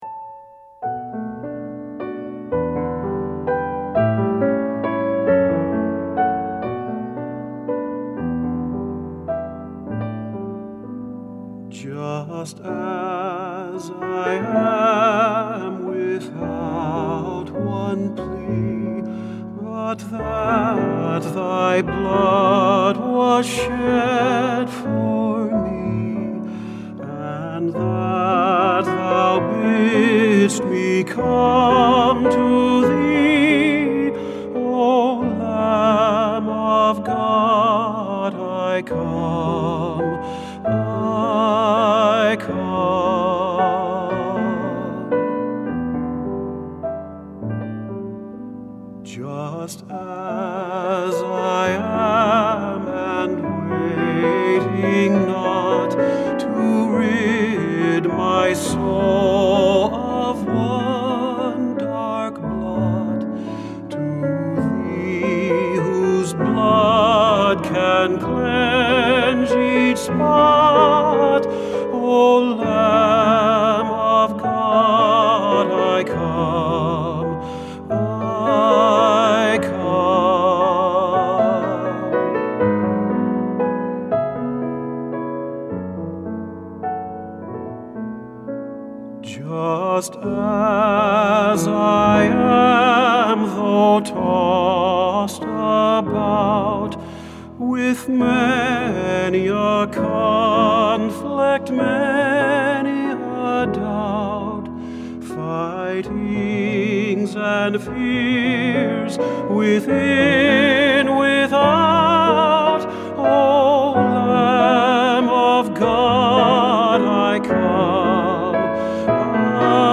Voicing: Vocal Collection